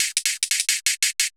Index of /musicradar/ultimate-hihat-samples/175bpm
UHH_ElectroHatA_175-05.wav